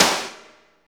52.10 SNR.wav